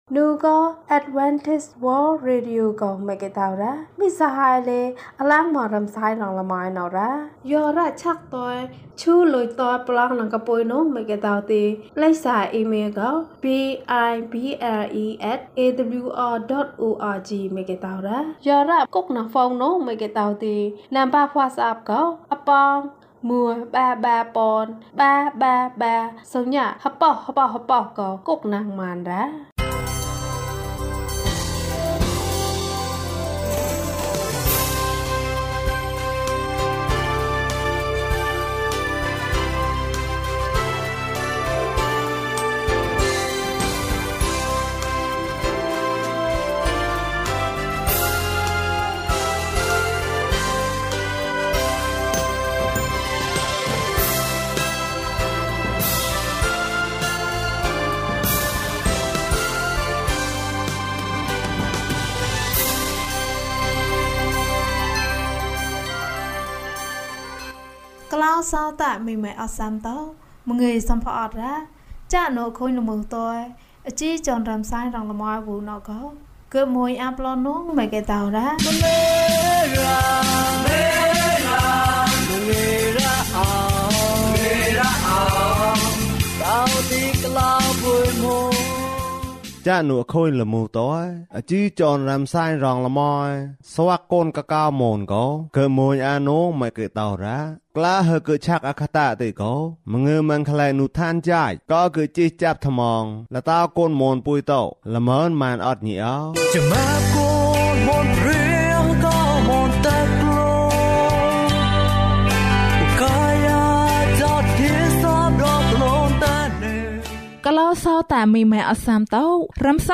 ခရစ်တော်ထံသို့ ခြေလှမ်း ၅၁ ကျန်းမာခြင်းအကြောင်းအရာ။ ဓမ္မသီချင်း။ တရားဒေသနာ။